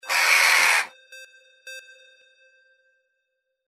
Crow Jackdaw 01A
Angry crow 'Caw'
Stereo sound effect - Wav.16 bit/44.1 KHz and Mp3 128 Kbps